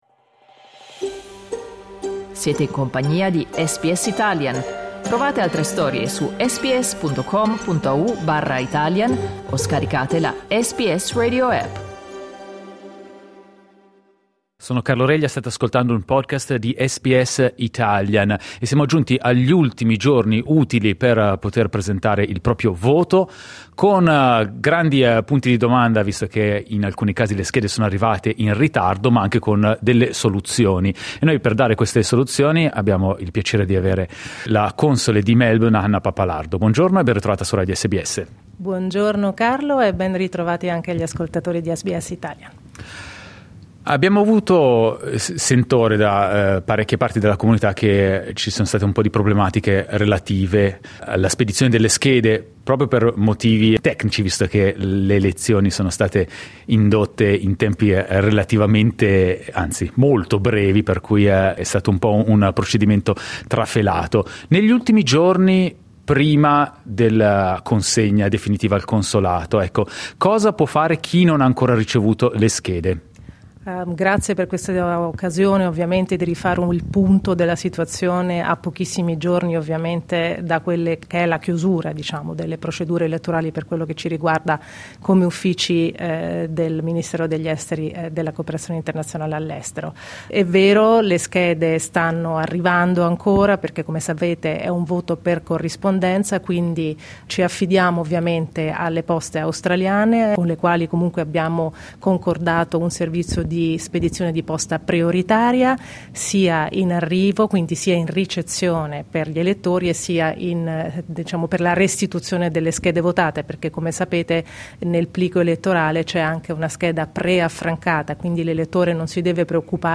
In questa intervista la console di Melbourne Hanna Pappalardo spiega a SBS Italian cosa fare in questi ultimi giorni disponibili per votare in caso di ritardi nella consegna delle schede.